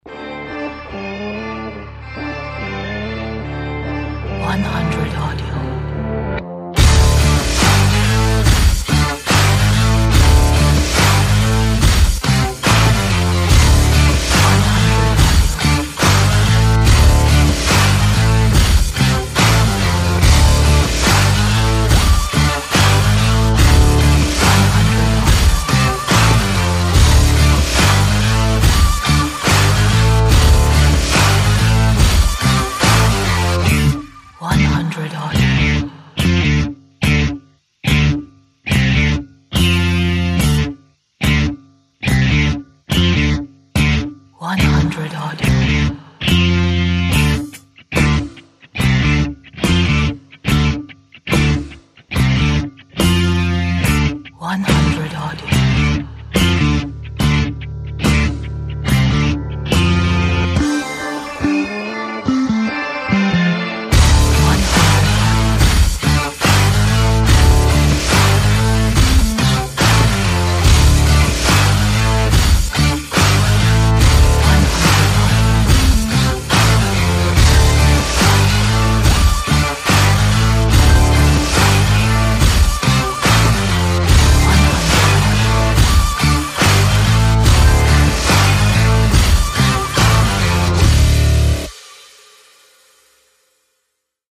A powerful rhythmic rock track. Loud, bright shuffle.